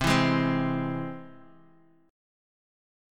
C+ Chord
Listen to C+ strummed